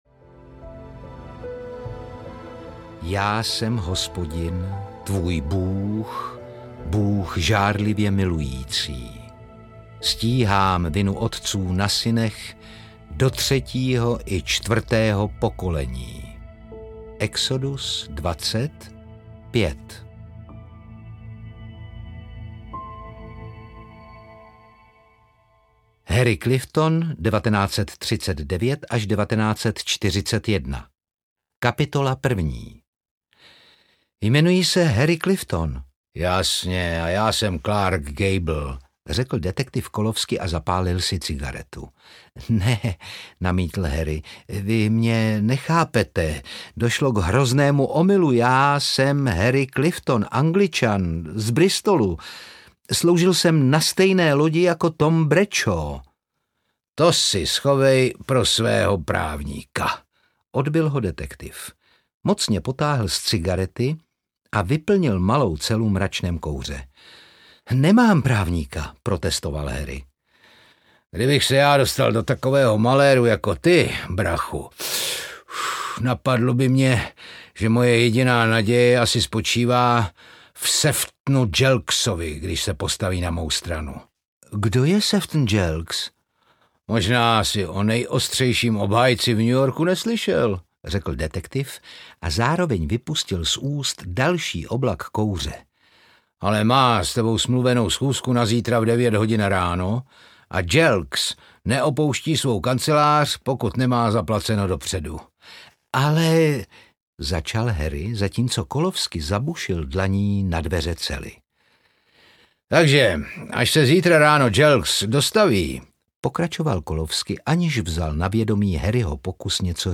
Otcovy hříchy audiokniha
Ukázka z knihy
• InterpretOtakar Brousek ml.